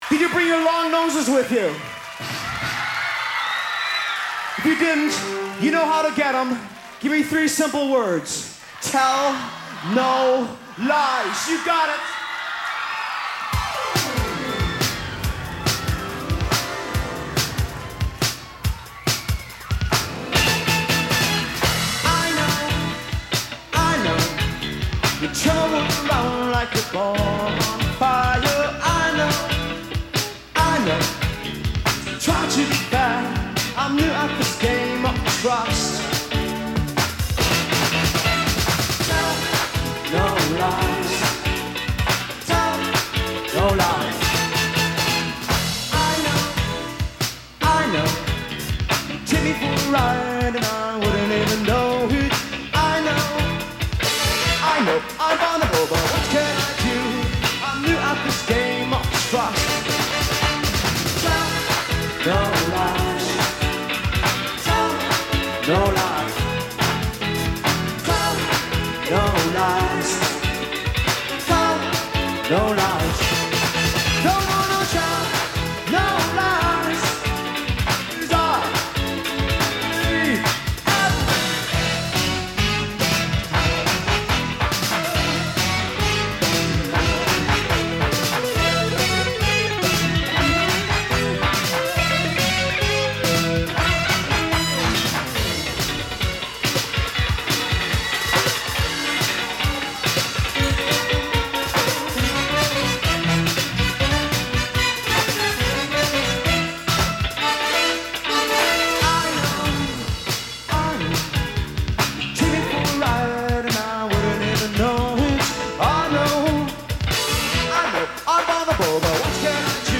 guitar, vocals
keyboards
drums